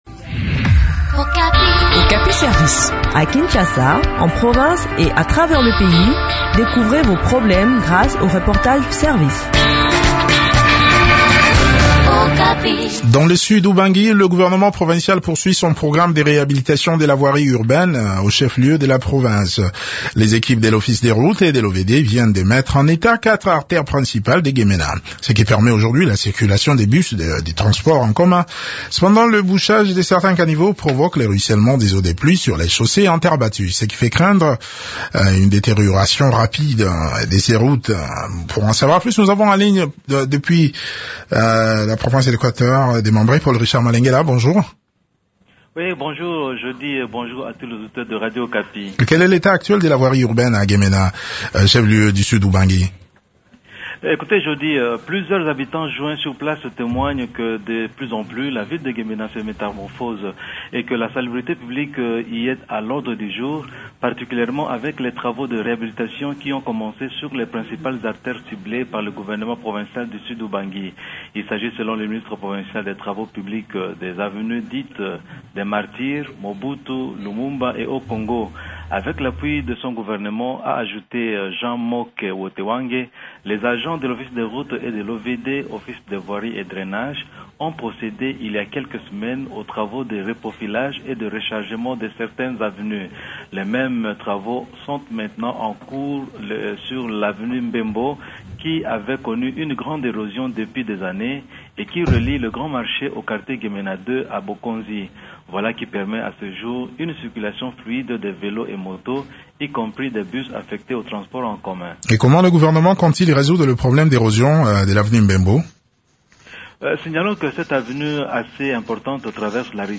s’entretient sur ce sujet avec Jean Moke Wotewange, ministre provincial des Infrastructures, travaux publics et voies de communication